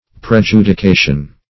Prejudication \Pre*ju`di*ca"tion\, n.